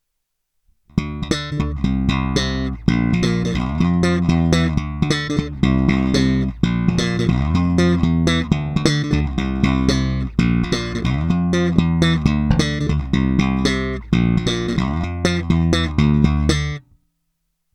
Opět nejdříve jen rovnou do zvukovky a ponecháno bez úprav, jen normalizováno.
Slap na oba snímače